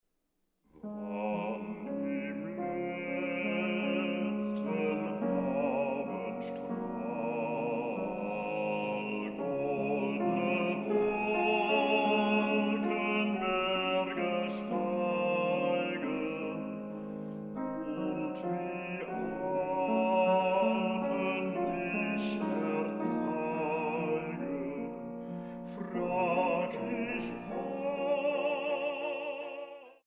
Bassbariton
Klavier